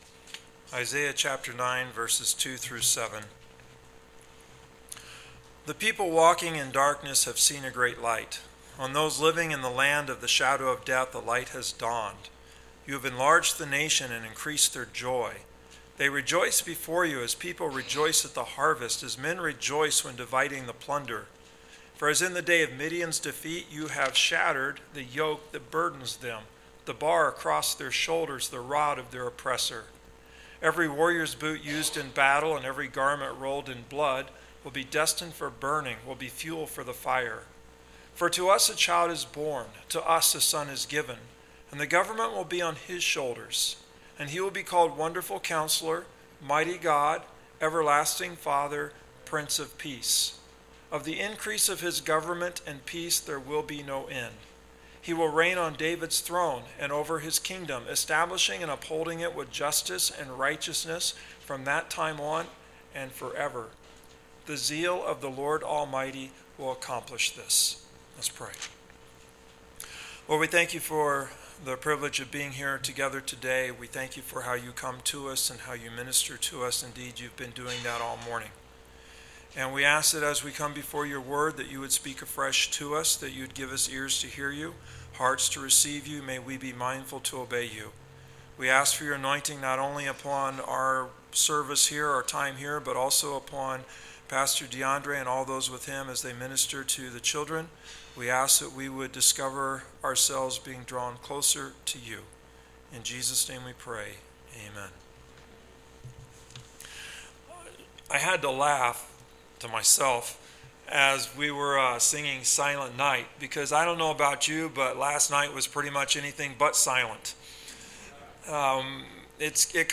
Gospel of Luke Sermons